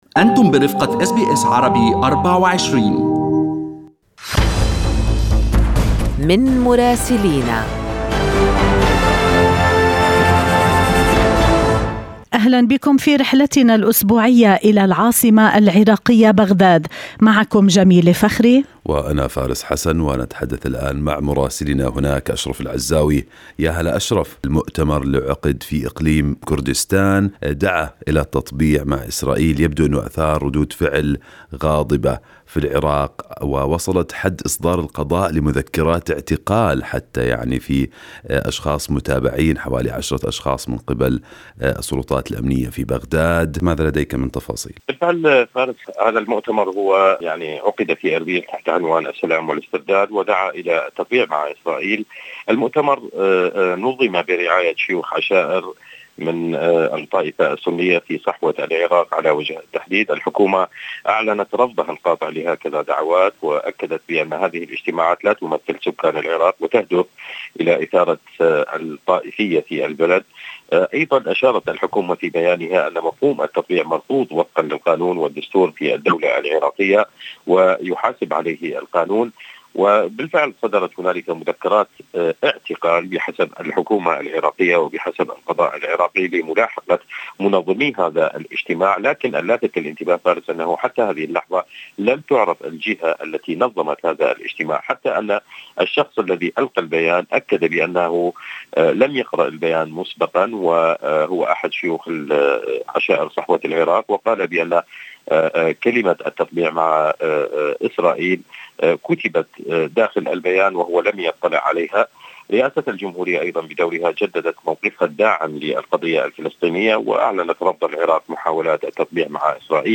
من مراسلينا: أخبار العراق في أسبوع 1/10/2021